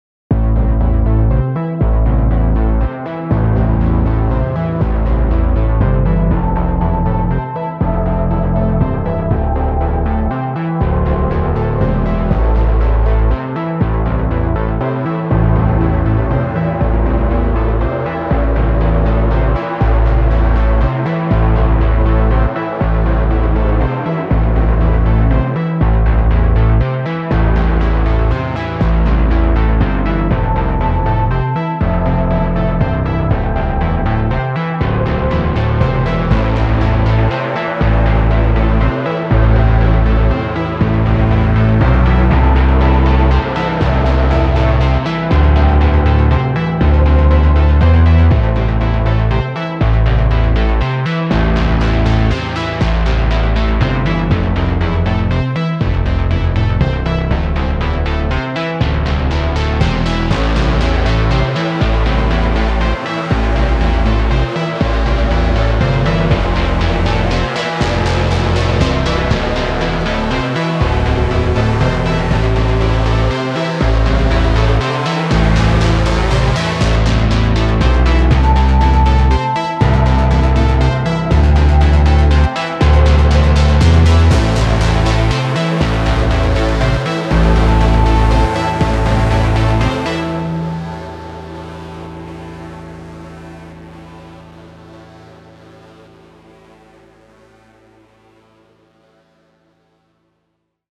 CINEMATIC SYNTH ARPEGGIO
Suspense / Tension / Synths / Film